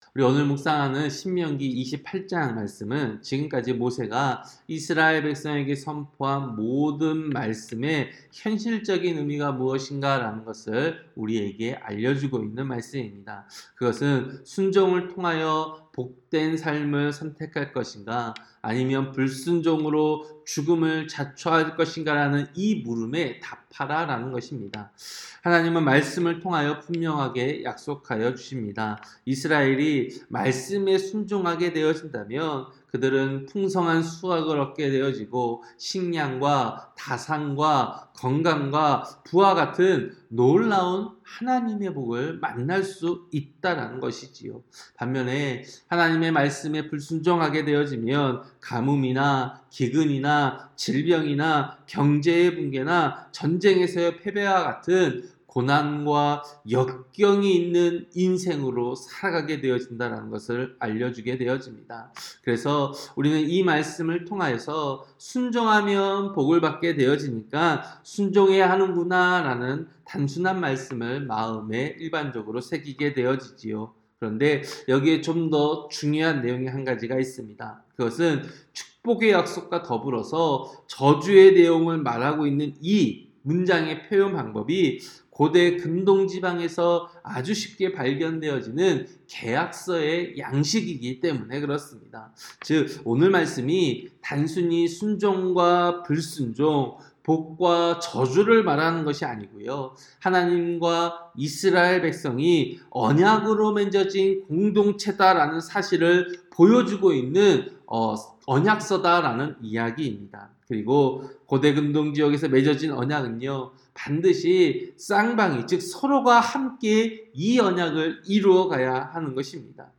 새벽설교-신명기 28장